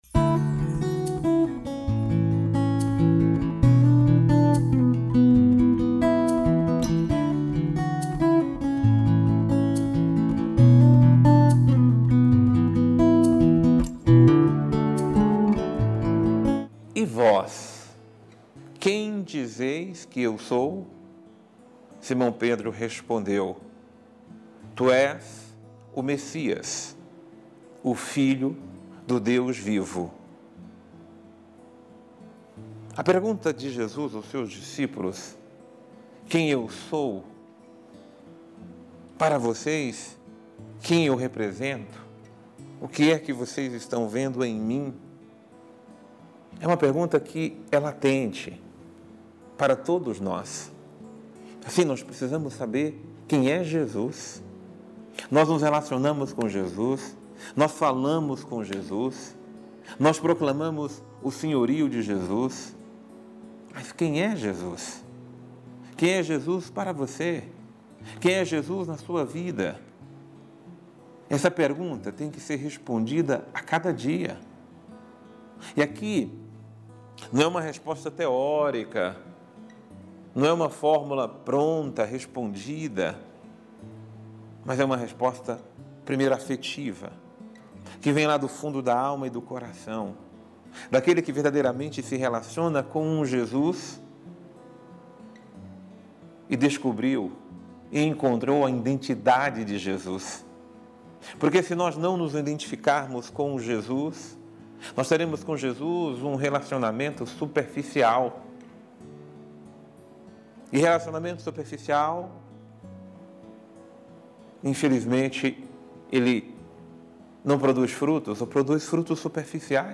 Homilia | Encontremos a verdadeira identidade de Jesus